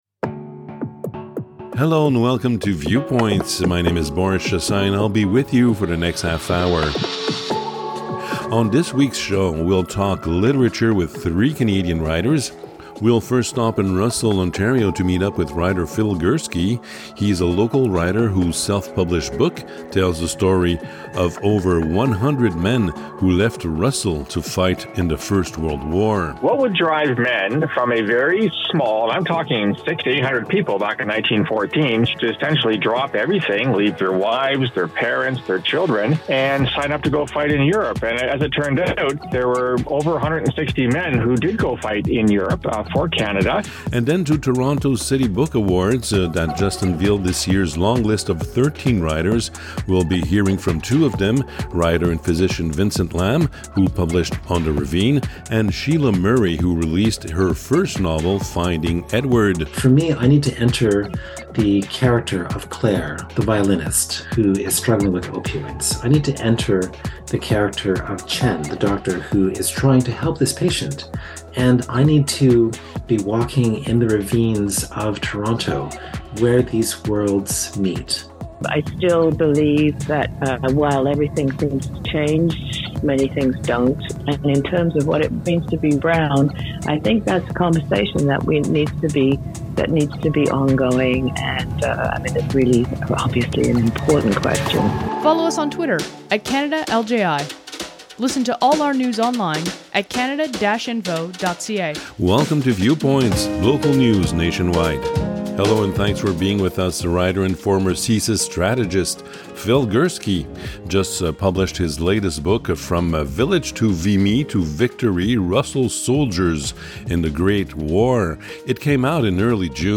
On today’s show: We’ll talk literature with three Canadian writers.